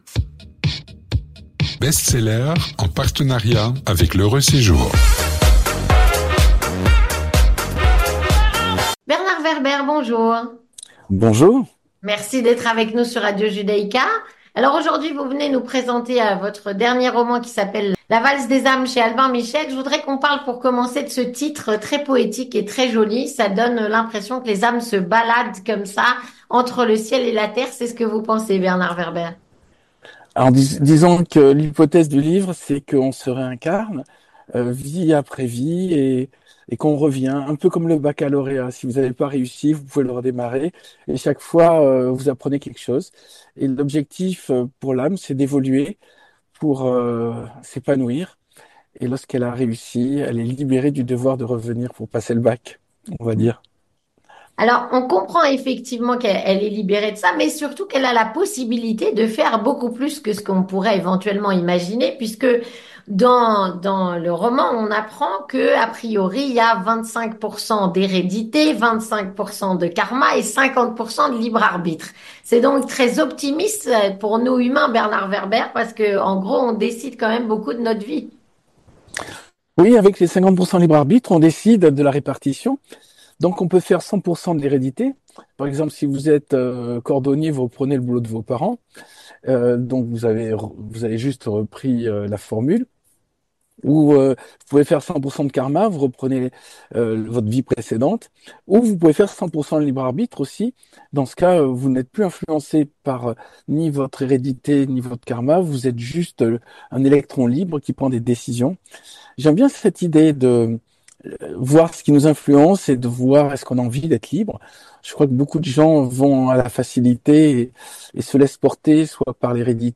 Avec Bernard Werber, écrivain, qui nous parle entre autres de spiritualité juive, mais pas que, de combat entre le bien et le mal, et de lumière, tous des thèmes traités dans son dernier roman.